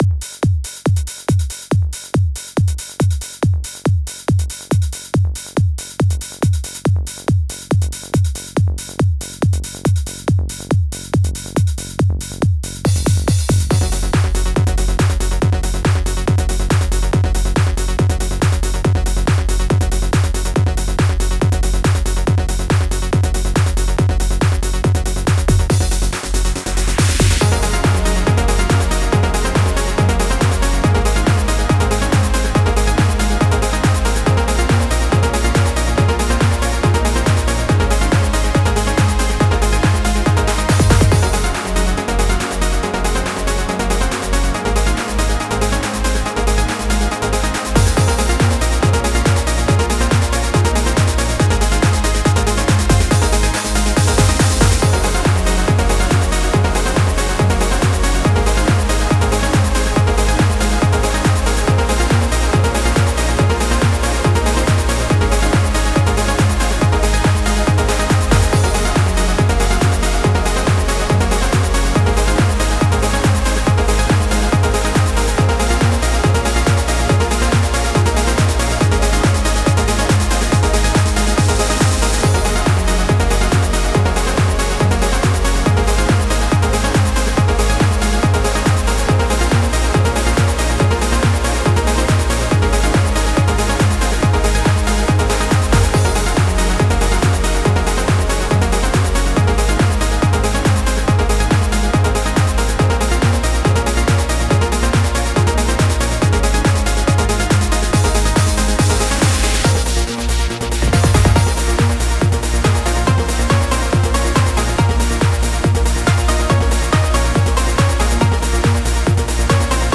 Stil: Trance